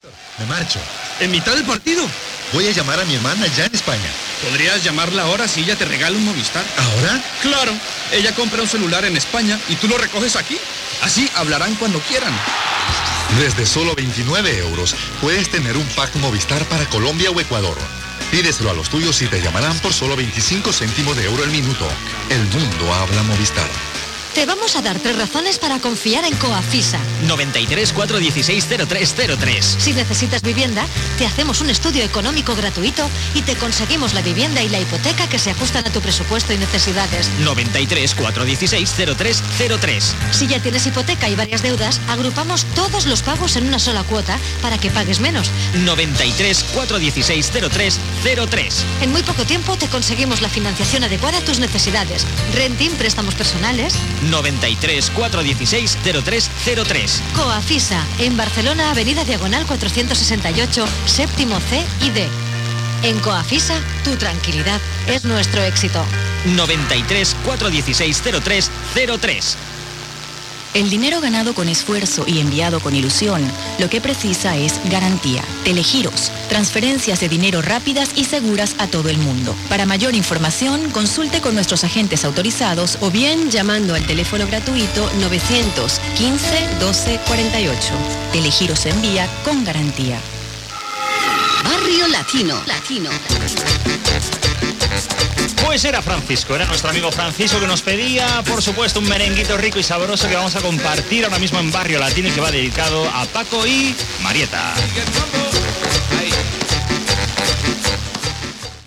Publicitat, indicatiu del programa i tema musical
FM